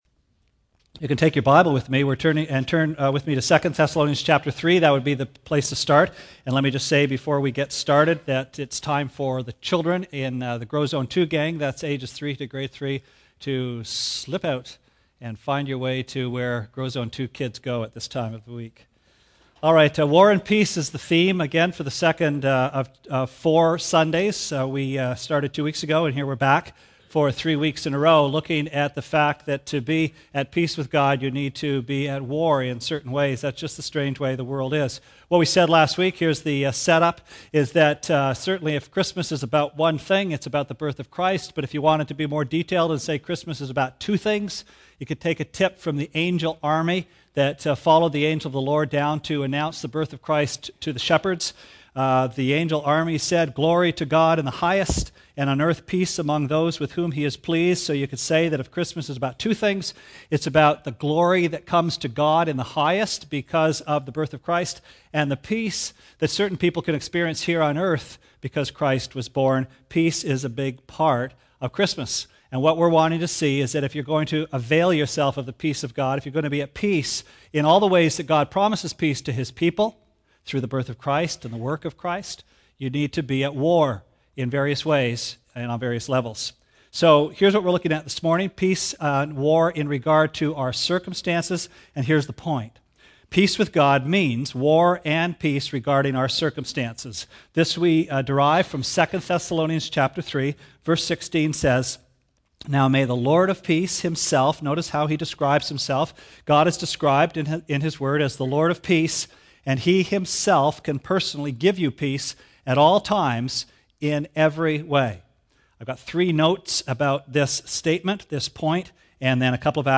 Sermon Archives - West London Alliance Church
West London Alliance Church is a church dedicated to making known the greatness of God in the city of London, Ontario.